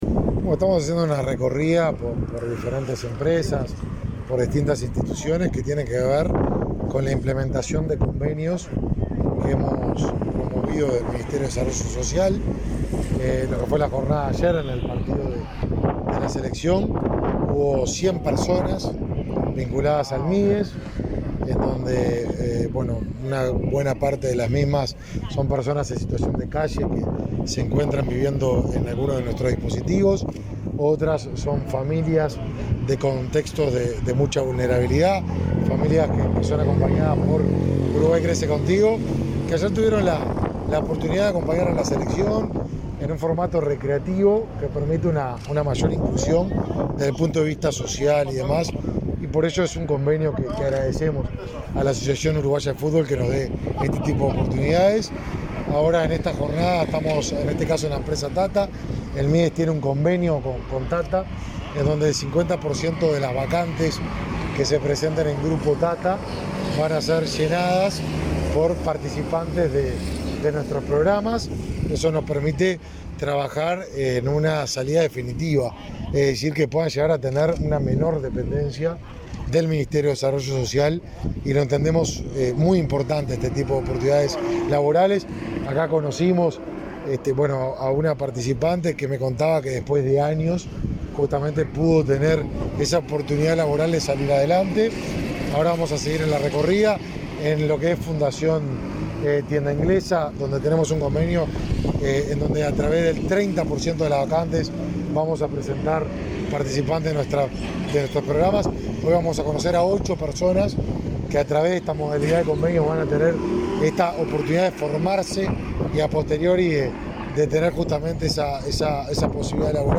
Declaraciones a la prensa del ministro de Desarrollo Social, Martín Lema, al visitar la empresa TA-TA
Al finalizar, efectuó declaraciones a la prensa.